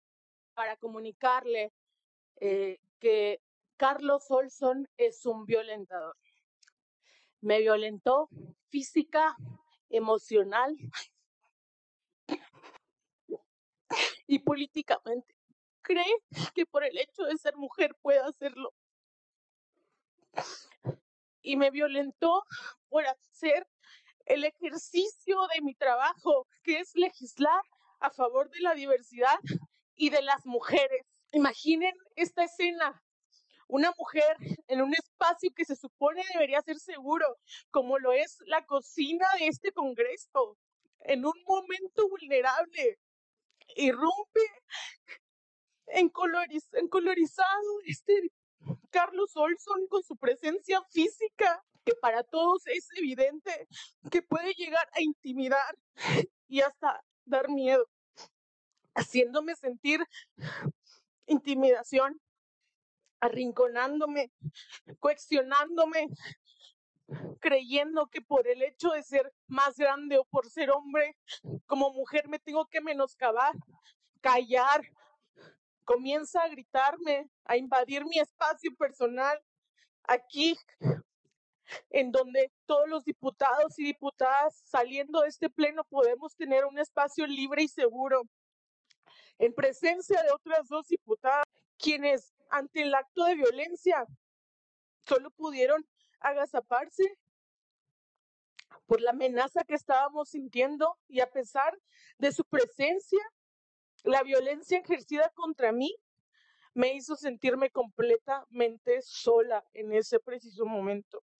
Noticias Chihuahua: Noticias Chihuahua En la sesión del Congreso de Chihuahua, la diputada Irlanda Márquez del Partido del Trabajo, reprochó al panista Carlos Olson irrumpir con gritos y reclamos a la cocina del recinto. La legisladora relató que se encontraba en compañía de dos mujeres mas que no alcanzaron a defender, donde le exigiría con gritos que deberían renunciar a su cargo.